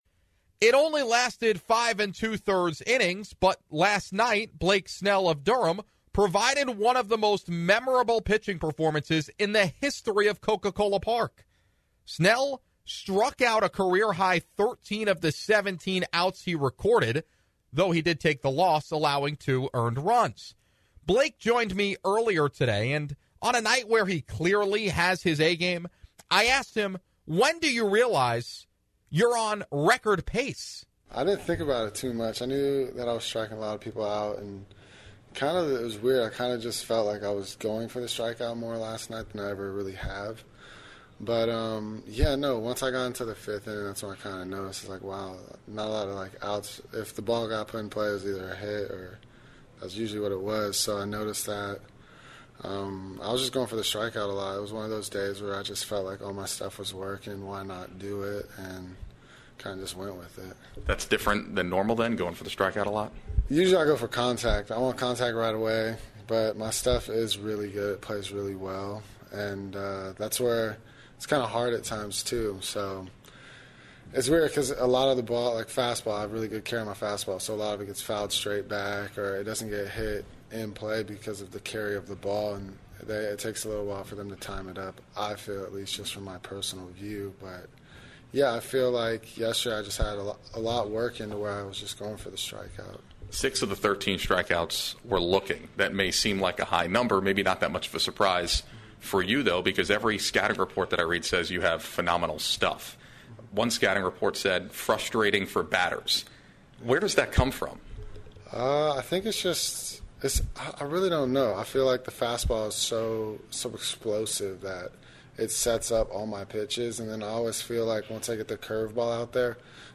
INTERVIEW with Bulls P Blake Snell